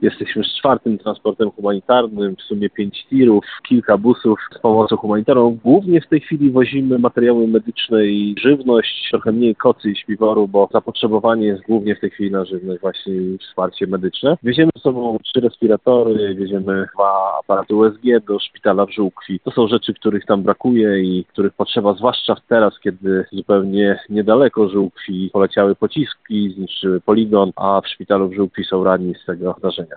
– Przejeżdżamy przez przejście w Hrebennem, jest tu teraz spokojnie – mówi prezydent Zamościa Andrzej Wnuk.